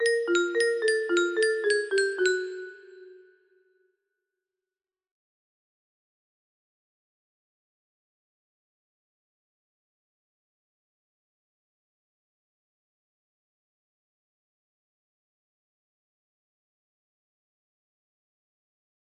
Creepy thing (solo) music box melody